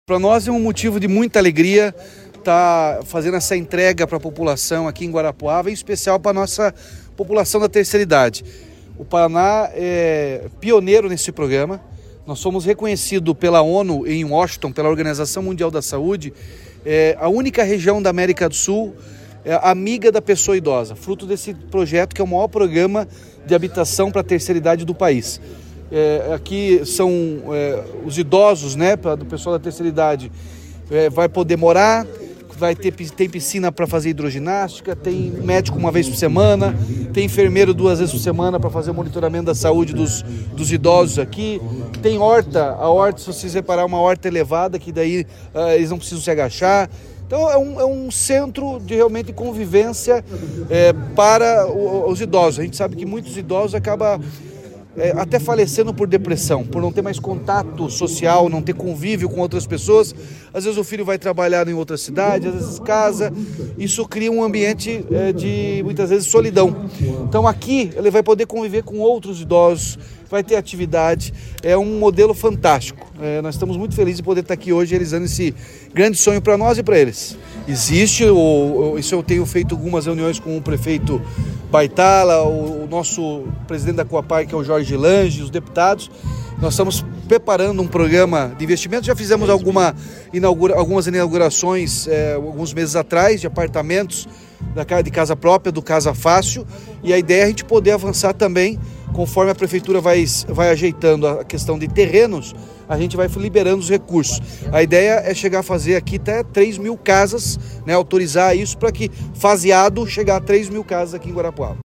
Sonora do governador Ratinho Junior sobre o Condomínio do Idoso de Guarapuava